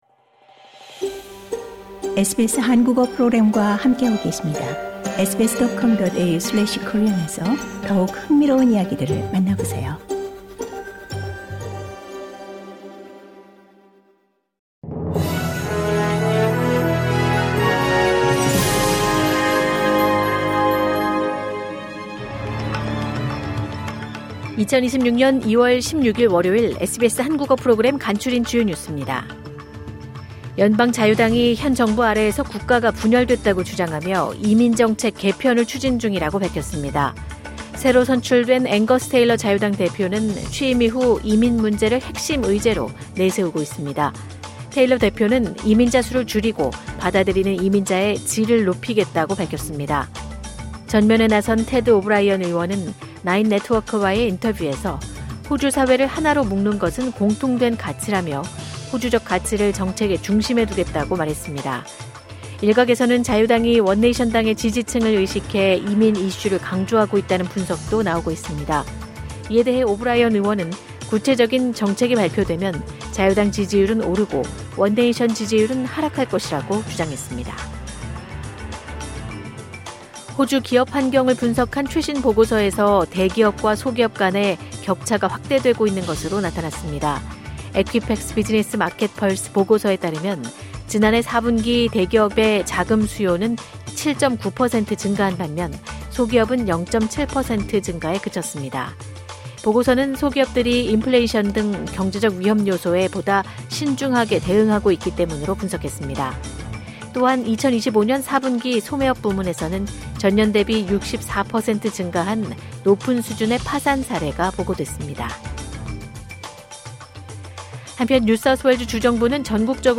경찰 “잘못된 인물 납치, 안전히 귀가” 호소 이 시각 간추린 주요 뉴스 LISTEN TO 호주 뉴스 3분 브리핑: 2026년 2월 16일 월요일 SBS Korean 03:46 Korean 연방 자유당이 현 정부 아래에서 국가가 분열됐다고 주장하며 이민 정책 개편을 추진 중이라고 밝혔습니다.